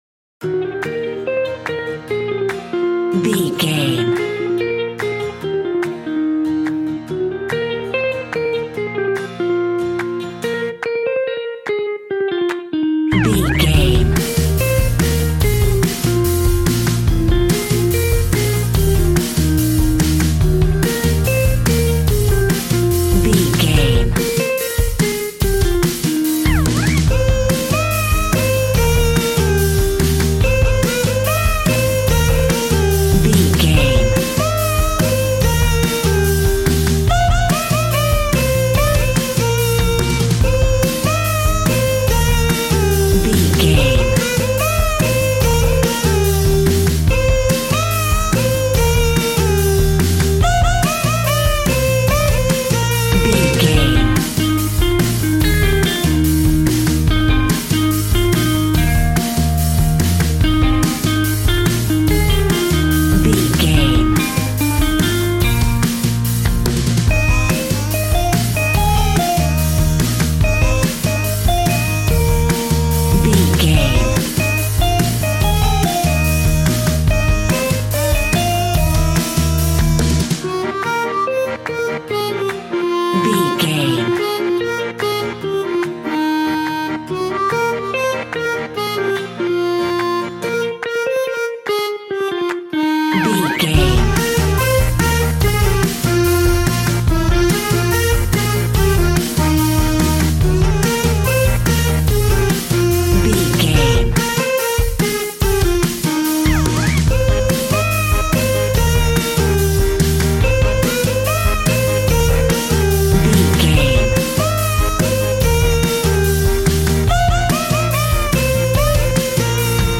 Ionian/Major
E♭
cheerful/happy
bouncy
electric piano
electric guitar
drum machine